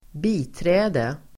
Uttal: [²b'i:trä:de]